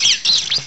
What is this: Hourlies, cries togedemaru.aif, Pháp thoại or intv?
cries togedemaru.aif